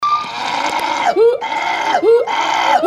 私自身が一番びっくりしたのは、フーロックテナガザルにしか無い声です（2秒間の音声ファイルです．発声者：フィジーさん．）
これは、ほかのテナガザルには無いとされている声で、がなり声というかなんというか。
（英語名はguttural growl）